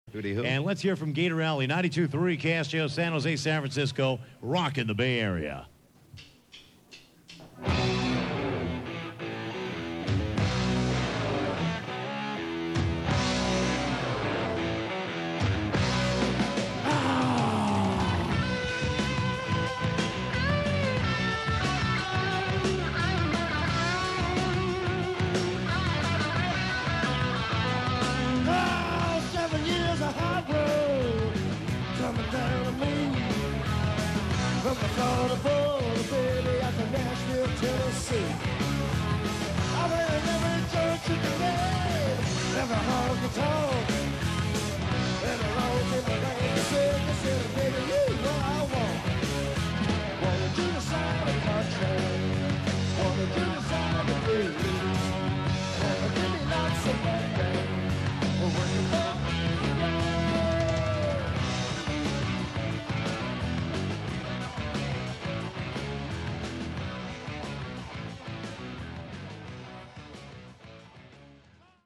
on air performance